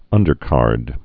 (ŭndər-kärd)